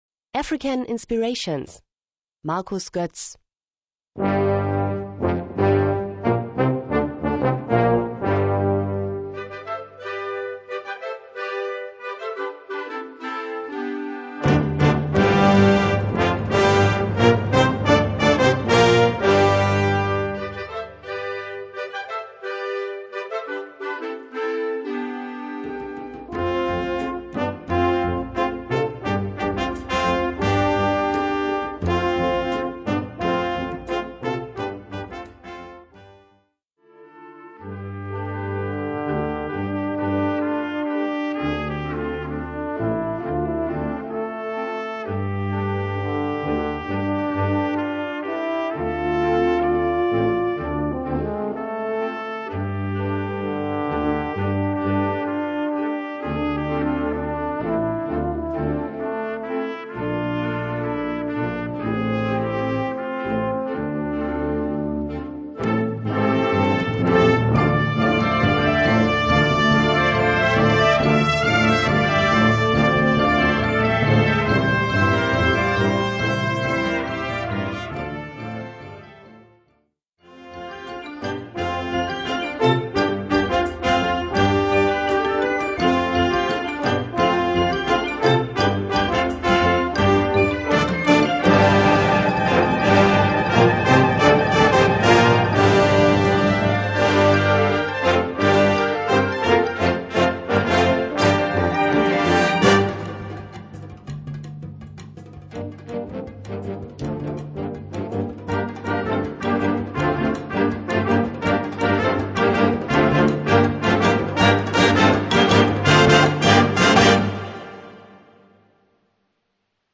Gattung: Rhapsody for Band
Besetzung: Blasorchester